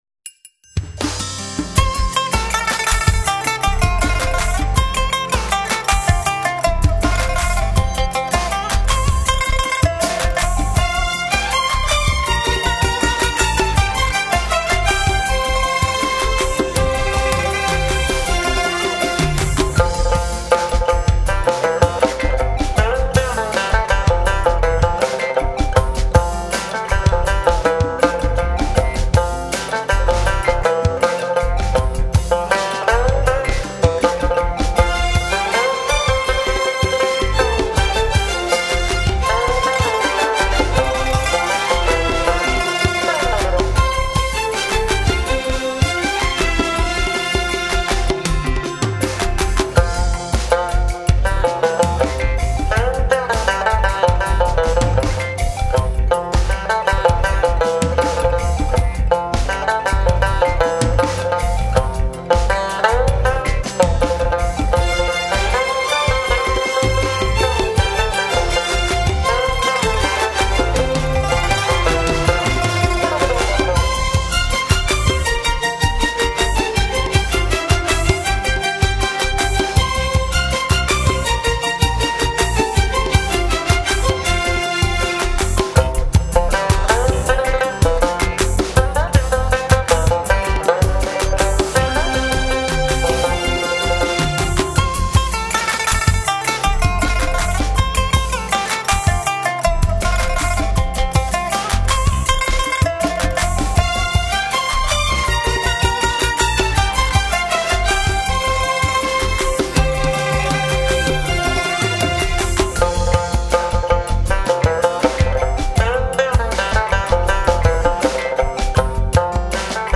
【三弦简介】